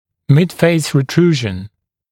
[‘mɪdfeɪs rɪ’truːʒn][‘мидфэйс ри’тру:жн]ретрузия средней части лица